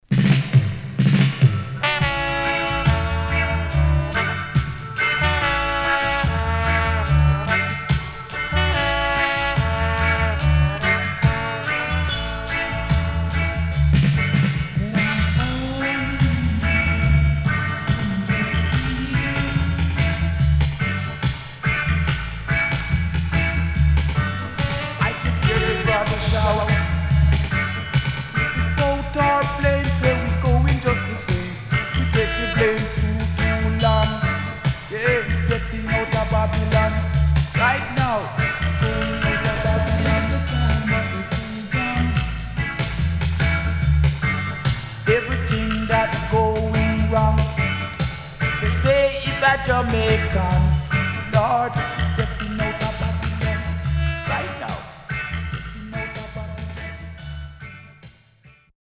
HOME > DISCO45 [VINTAGE]  >  SWEET REGGAE  >  70’s DEEJAY
Sweet Vocal & Deejay Cut
SIDE A:少しチリノイズ、プチノイズ入ります。